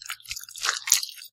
nether_extract_blood.5.ogg